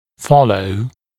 [‘fɔləu][‘фолоу]следовать (ч.-л., за ч.-л.)